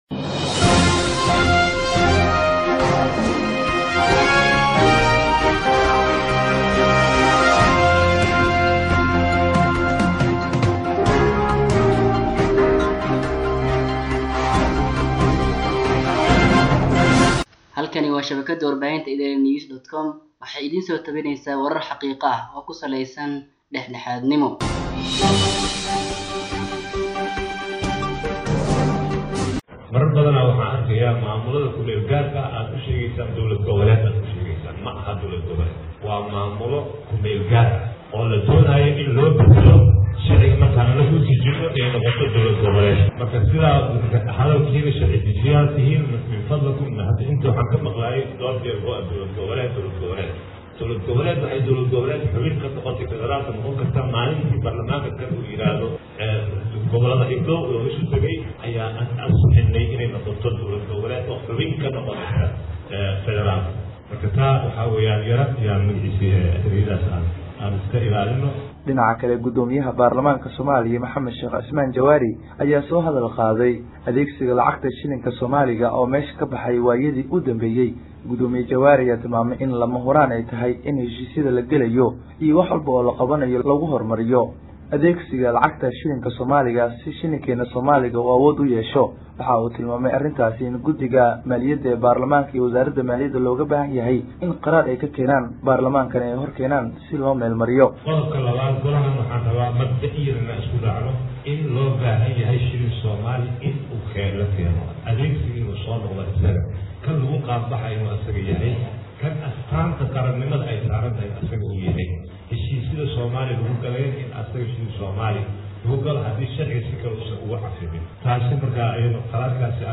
Prof. Jawaari ayaa hadalkan shalay ka sheegay kulankii baarlamaanka, isagoo tilmaamay in shuruucda dalka aysan waafaqsaneyn in maamullo KMG ah loogu yeero dowlad-goboleed.